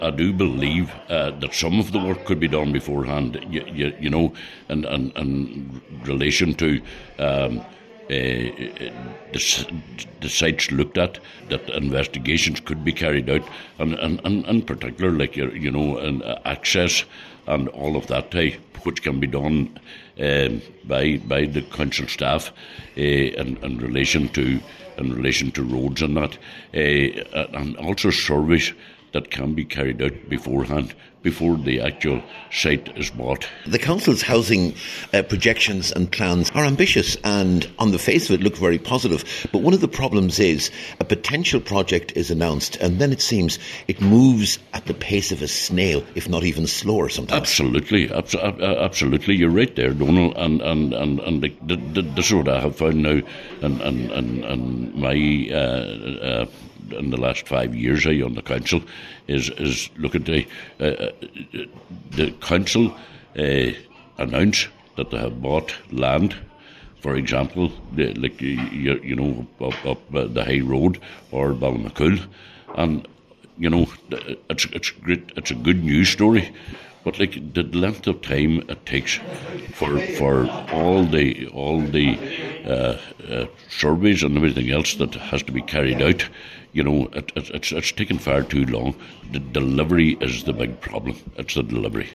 Cllr Donal Coyle was speaking after members were told that plans are progressing for the construction of flagship projects at High Street and Ballymacool, but a number of surveys and investigations need to be completed before the works start.